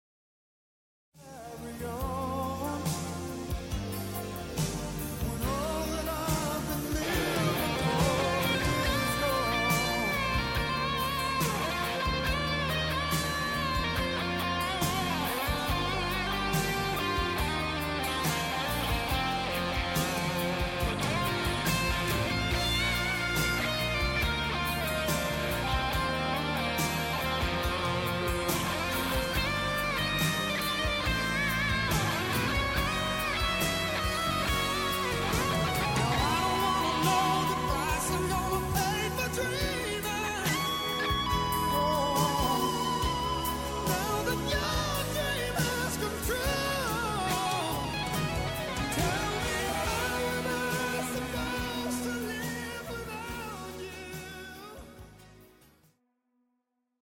thích hợp Mp3 Sound Effect Sagon mưa gió, thích hợp để đánh nhạc buồn.